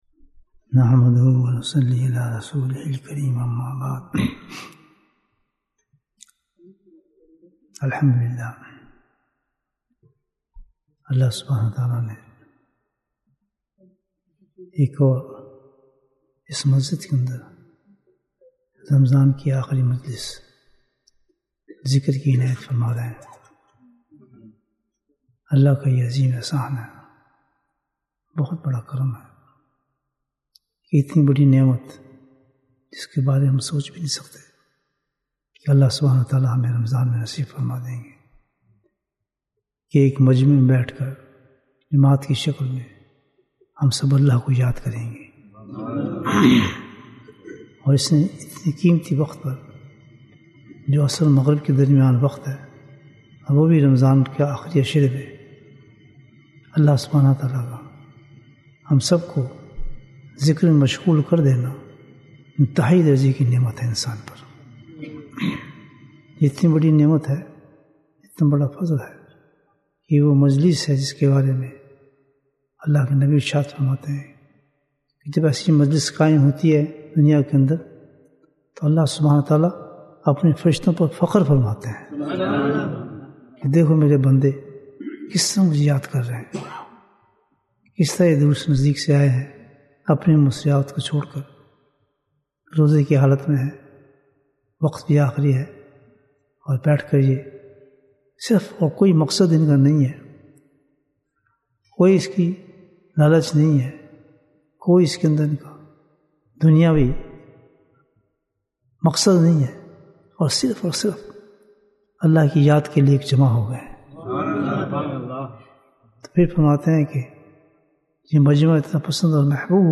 Bayan, 32 minutes 18th April, 2023 Click for English Download Audio Comments Why Do We Do Dhikr?